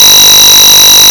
These sound effects are produced by the routine at 30464 when a teacher is giving lines to ERIC, EINSTEIN, ANGELFACE or BOY WANDER.